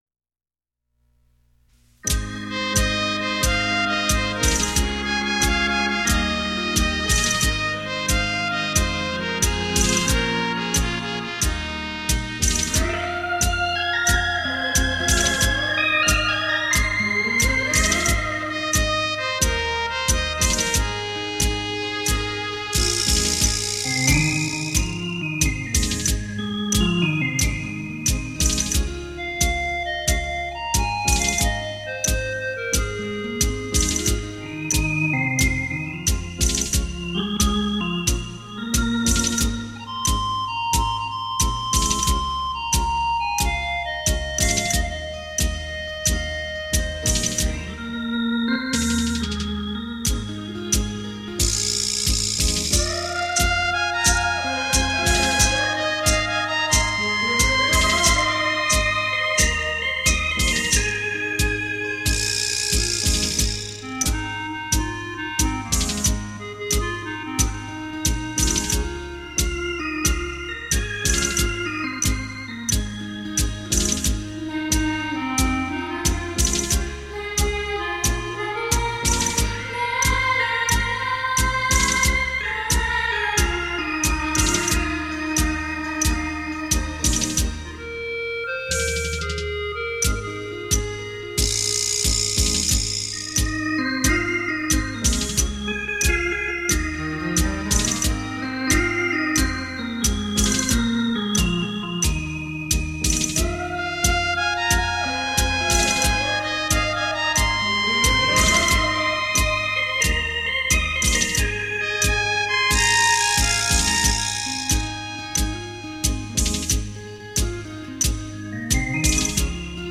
优美的旋律 清脆的音感